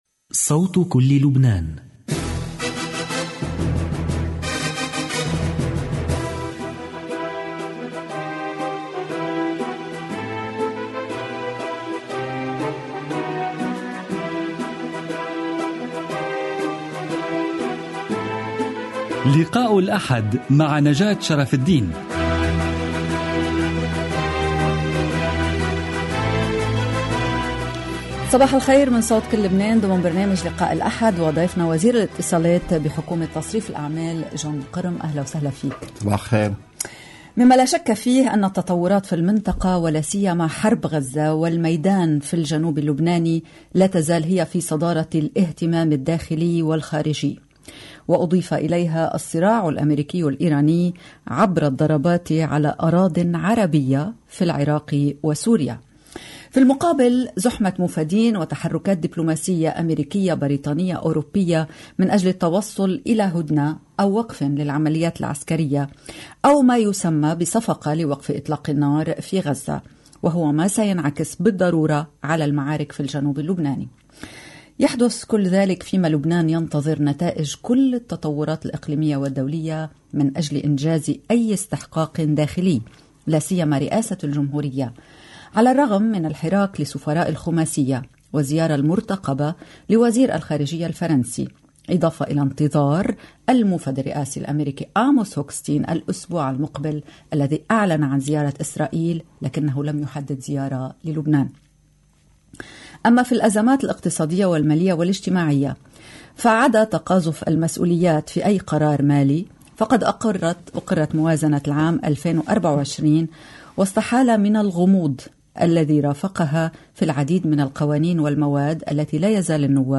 لقاء الأحد ضيف الحلقة وزير الاتصالات في حكومة تصريف الاعمال جوني القرم Feb 05 2024 | 01:01:59 1x 00:00 / 01:01:59 Subscribe Share RSS Feed Share Link Embed